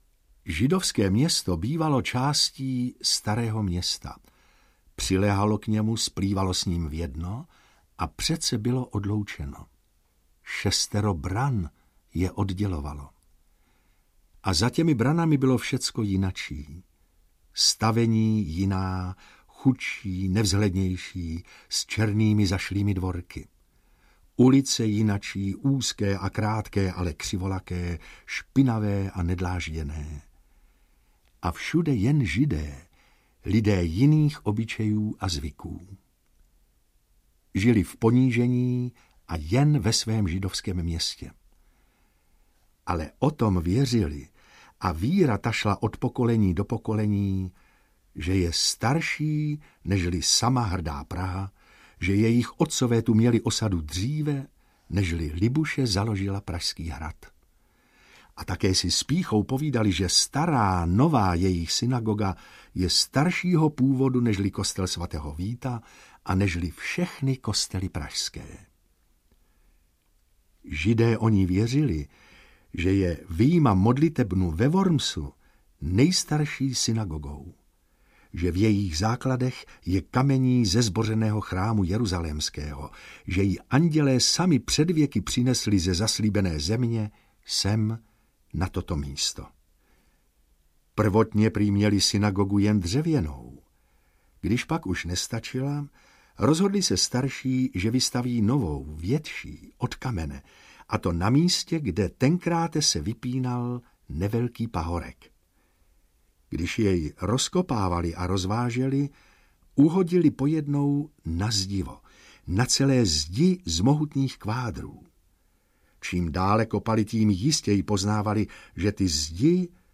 Židovská Praha - Praha v pověstech, mýtech a legendách audiokniha
Ukázka z knihy
• InterpretJosef Somr, Hana Maciuchová, Otakar Brousek st.